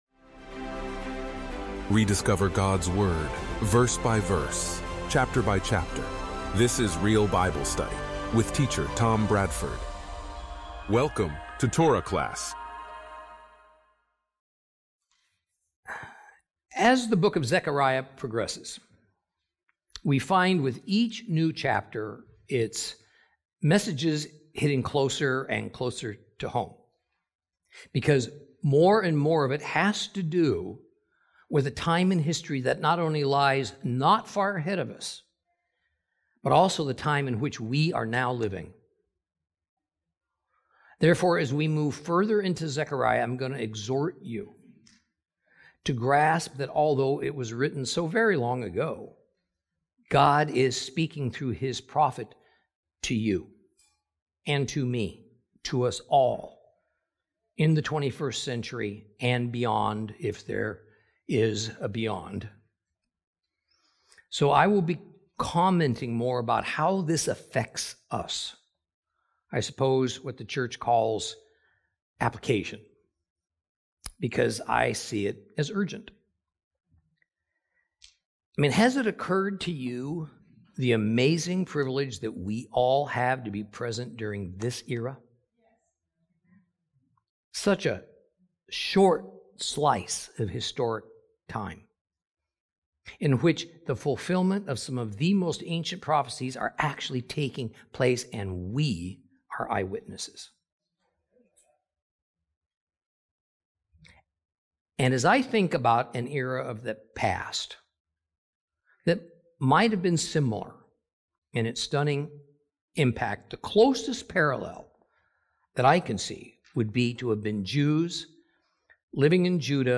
en-audio-zechariah-lesson-15---zechariah-ch-8.mp3